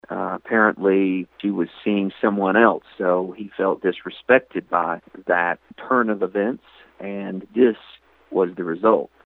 Commonwealth Attorney Chuck Slemp Was Happy With The Outcome In Regards To Virginia’s New Sentencing Laws.